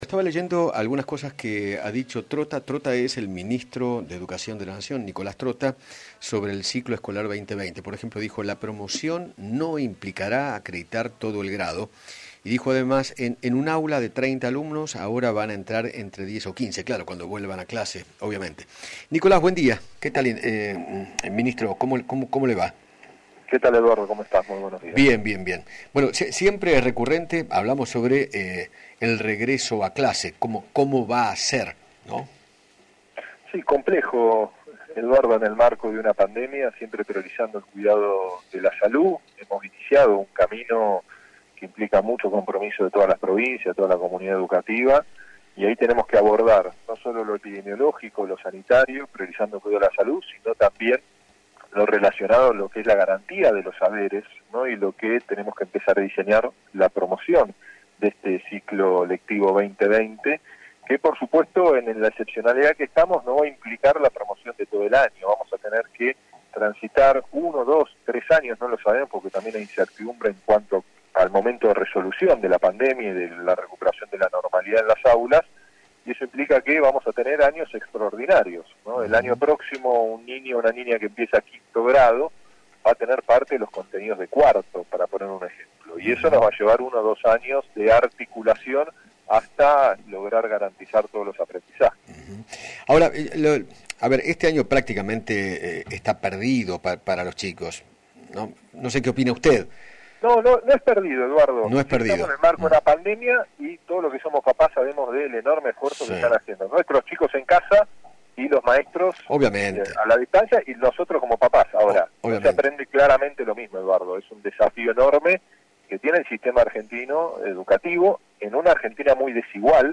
Nicolás Trotta, ministro de Educación de la Nación, dialogó con Eduardo Feinmann sobre la vuelta a clases y contó cómo será la adecuación de los contenidos de las materias según el grado. Además, se refirió al sistema evaluativo.